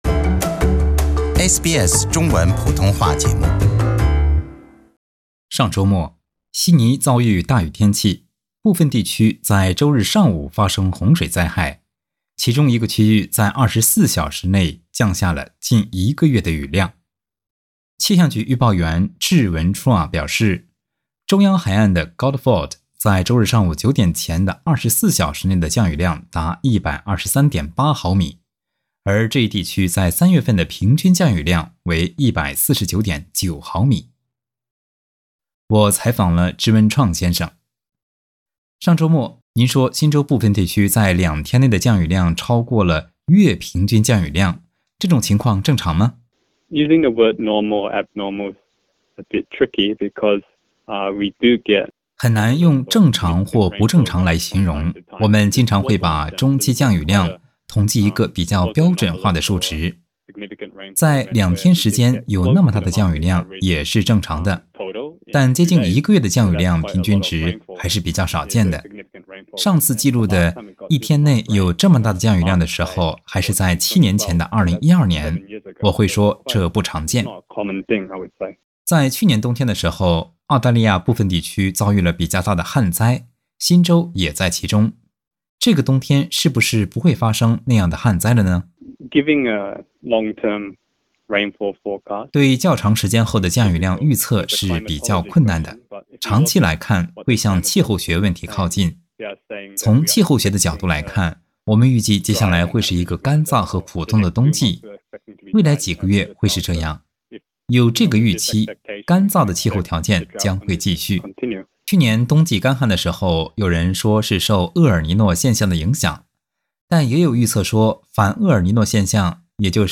接下来请您收听详细的采访内容。